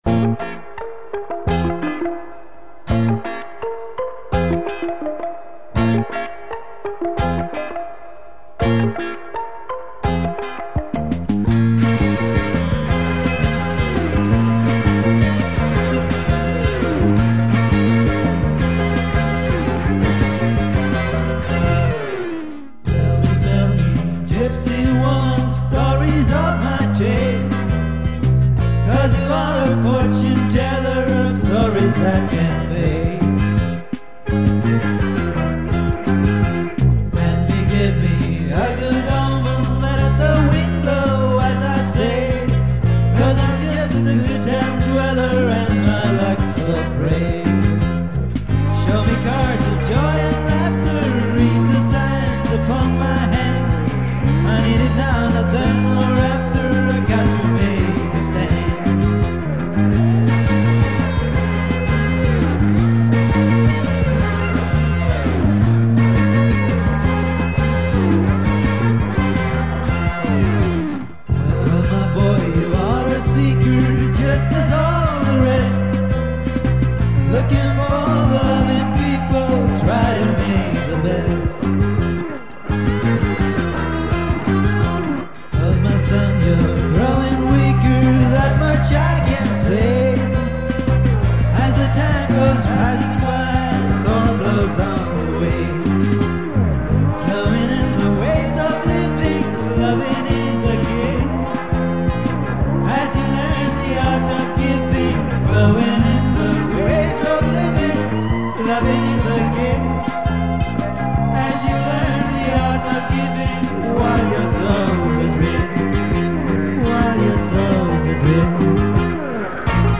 Later on they bought a Tascam Porta Studio taperecorder.